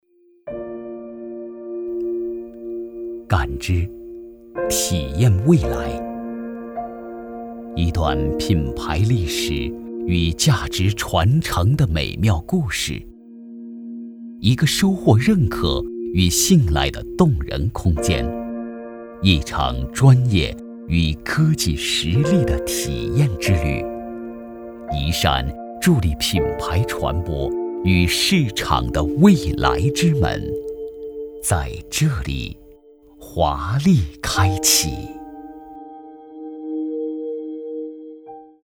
广告-男46-磁性-东风商用.mp3